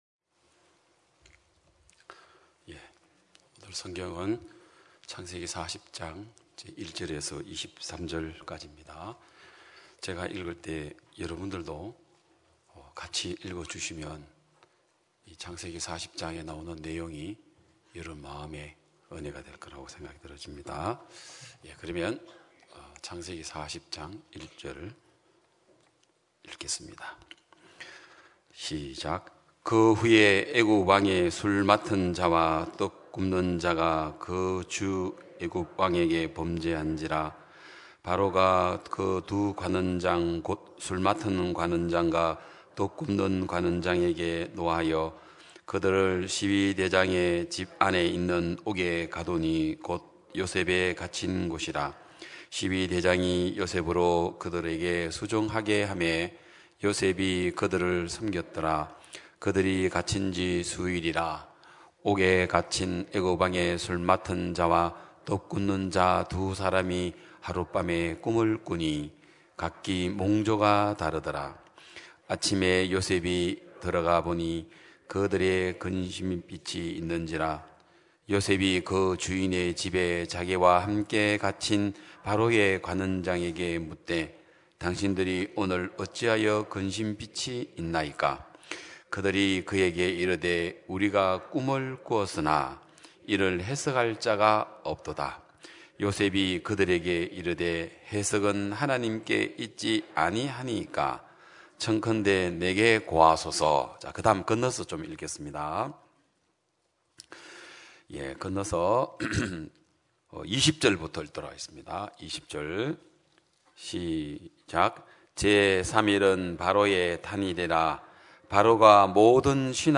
2022년 5월 8일 기쁜소식양천교회 주일오전예배
성도들이 모두 교회에 모여 말씀을 듣는 주일 예배의 설교는, 한 주간 우리 마음을 채웠던 생각을 내려두고 하나님의 말씀으로 가득 채우는 시간입니다.